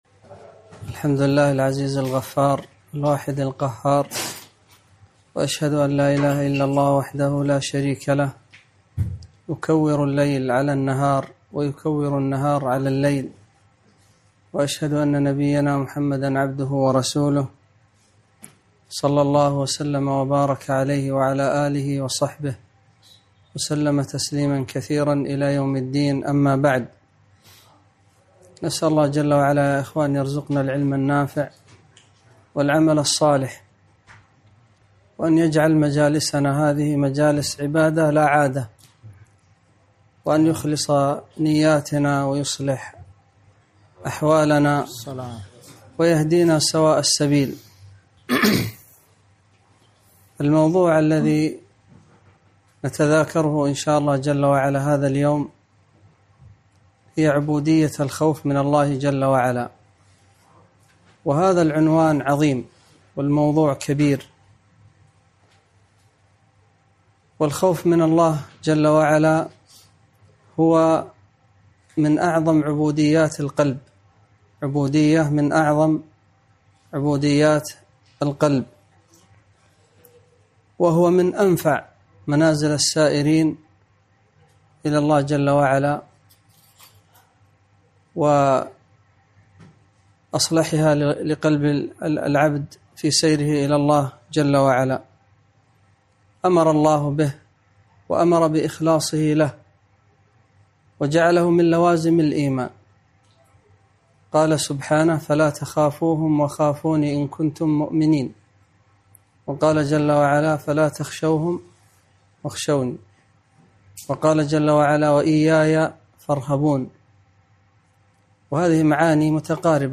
محاضرة - عبودية الخوف من الله تعالى